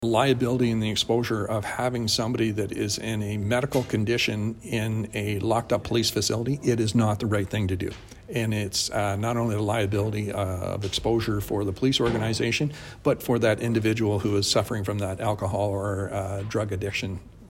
Police Chief Mike Callaghan told Belleville’s Police Services Board that the last place someone arrested for creating a disturbance or committing another crime while heavily intoxicated should be is in police custody and that it’s time smaller communities had “detox centres” separate from police services.